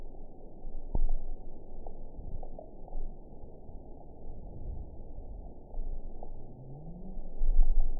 event 921751 date 12/18/24 time 21:23:11 GMT (11 months, 2 weeks ago) score 8.84 location TSS-AB03 detected by nrw target species NRW annotations +NRW Spectrogram: Frequency (kHz) vs. Time (s) audio not available .wav